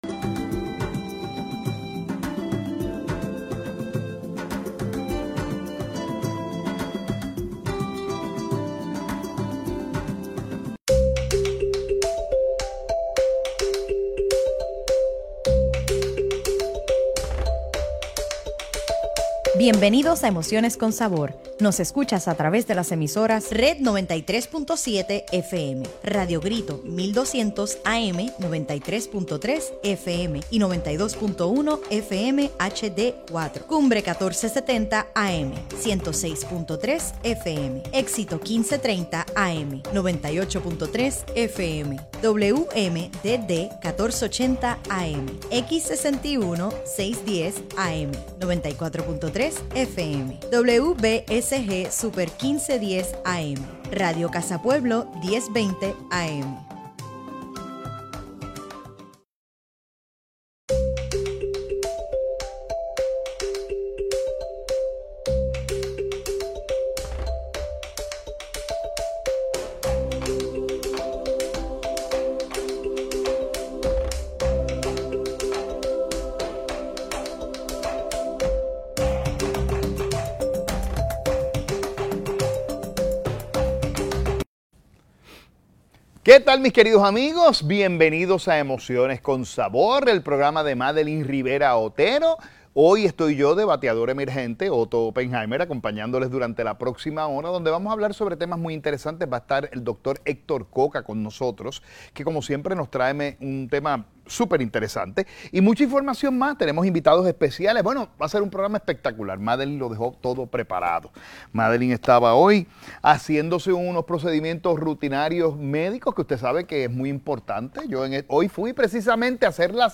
Hoy traemos una combinación perfecta de conocimiento, música y energía.